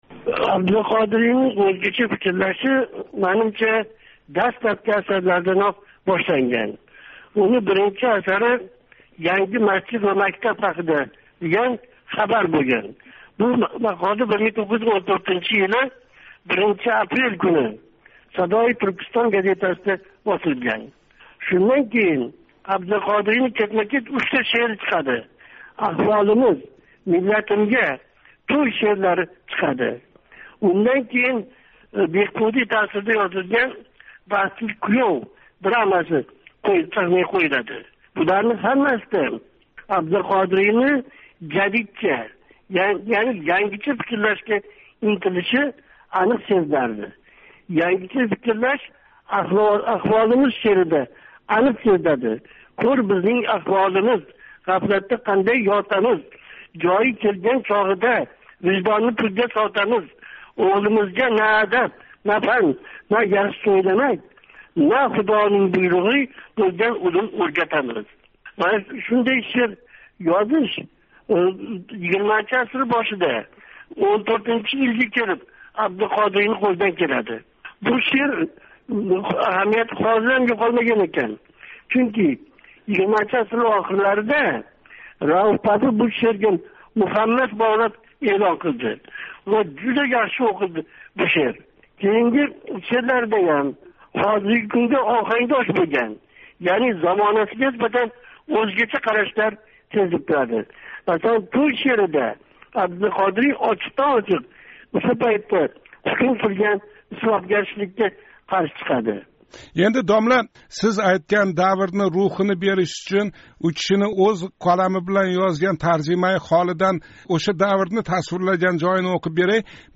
Қуйида ўша суҳбатдан парча эътиборингизга ҳавола қилиняпти.